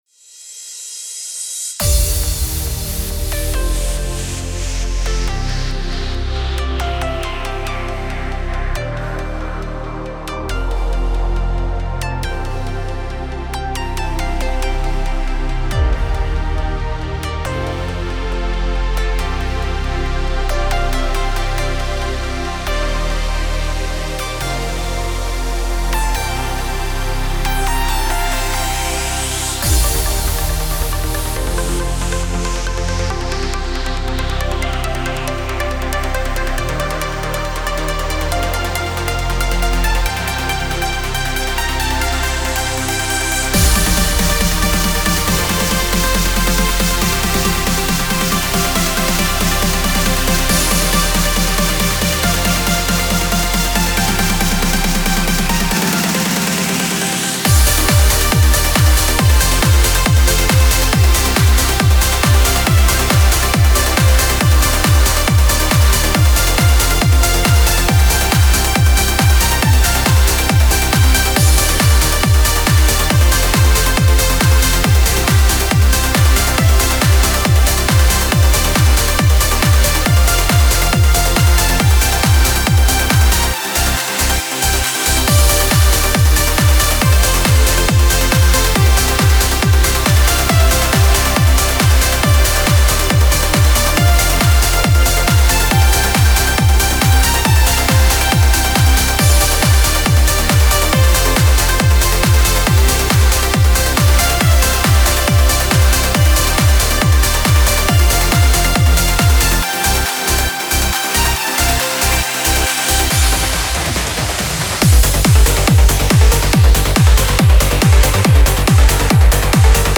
Type: Spire Midi Templates
Trance Uplifting Trance
⦁ 100 presets for Spire & ReSpire synthesizers.